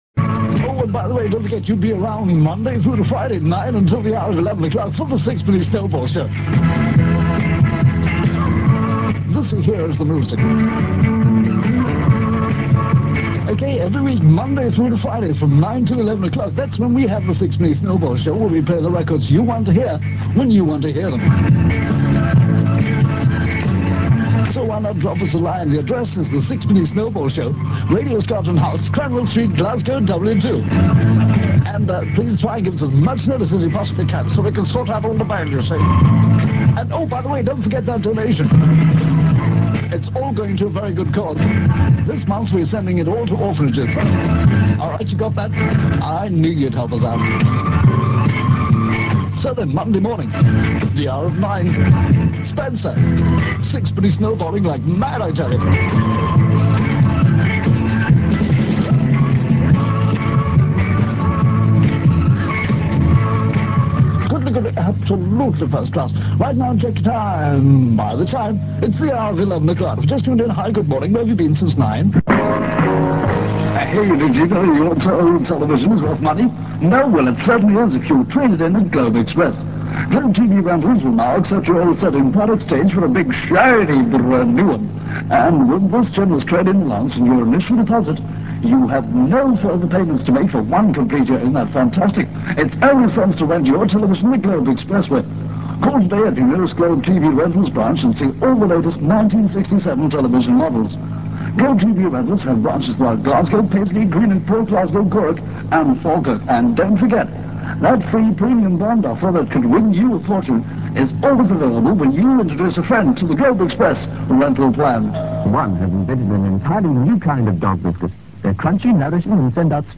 The first, from the Sixpenny Snowball Show, is taken from a documentary about the station made by the Forth hospital radio network in 1968. The second is from Swing Across Midday on 19th May 1967.